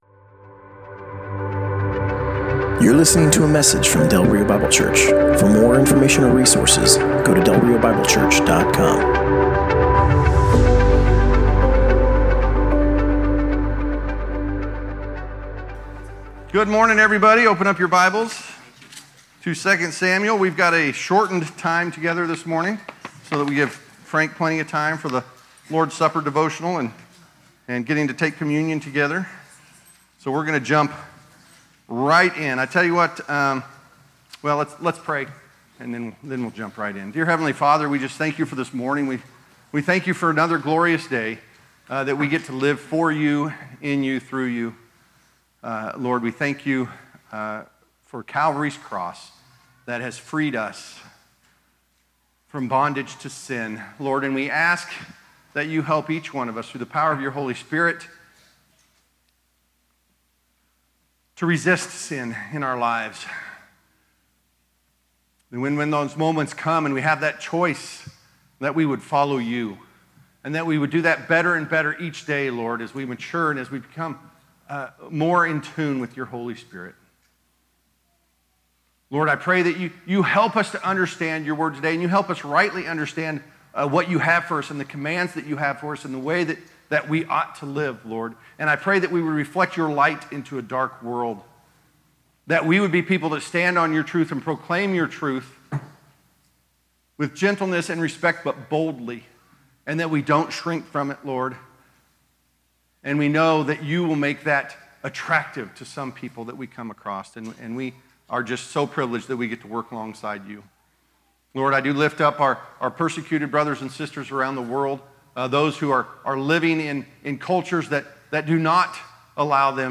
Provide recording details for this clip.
Passage: 2 Samuel 14: 25 - 15:12 Service Type: Sunday Morning